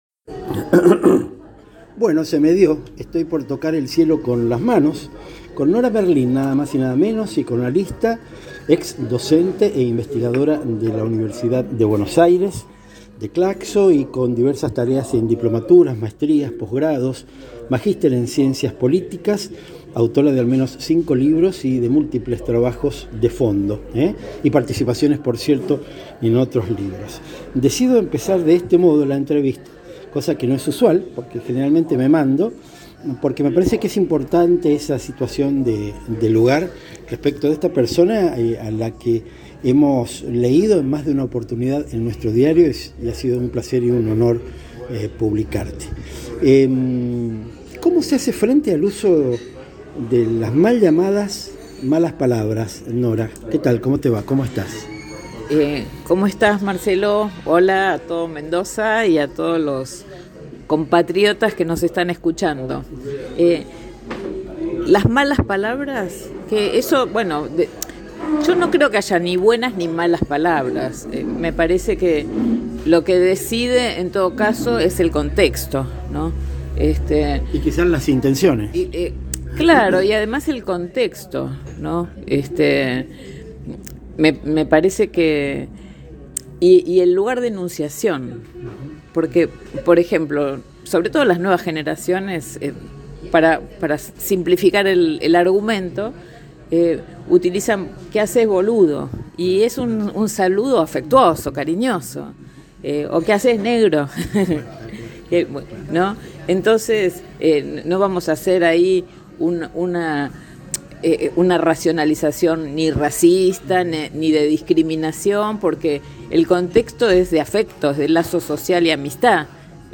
Luego del carraspeo del arranque, como queriendo aclarar la voz que se disponía frente a la primera de las entrevistas de este nuevo desembarco en Buenos Aires, lo que sigue es una charla interesantísima.
La hicimos sentados a una de las mesas del Café Cortázar, ubicado en Palermo, mientras todo indicaba que al gris de la mañana seguiría la lluvia.